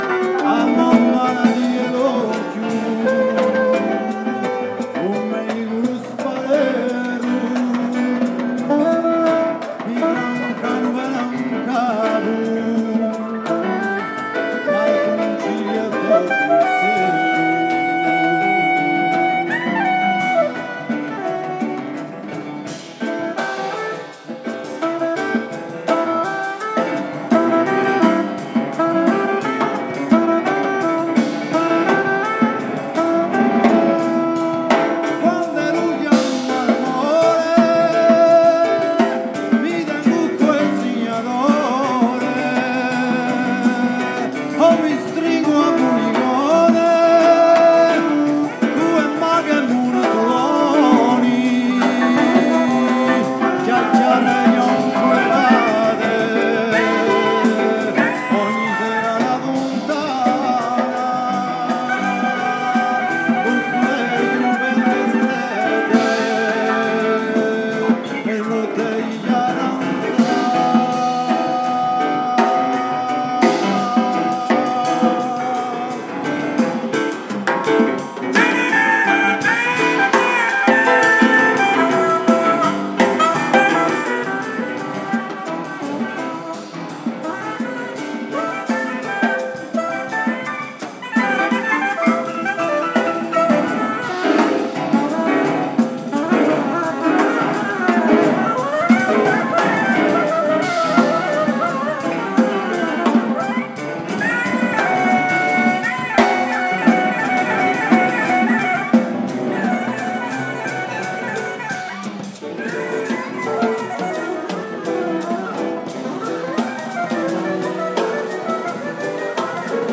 fisarmonica e tastiere
batteria
contrabbasso
tromba
sax e flauto